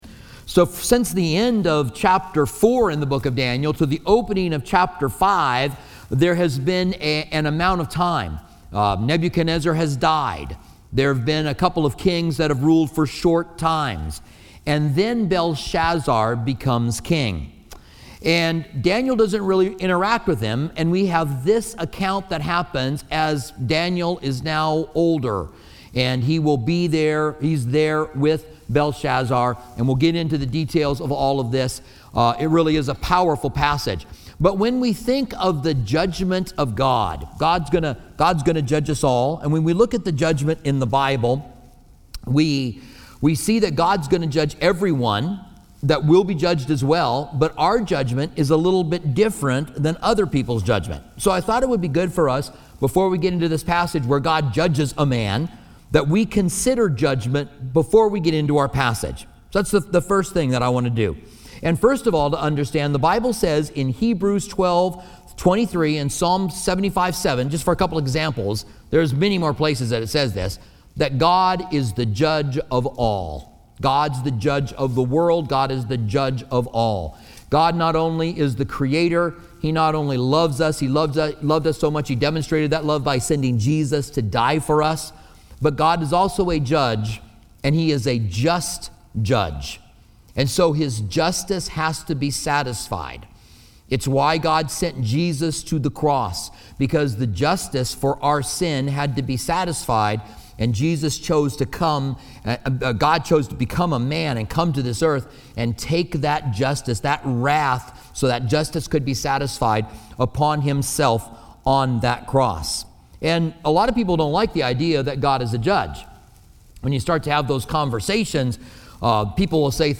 Listen to Pastor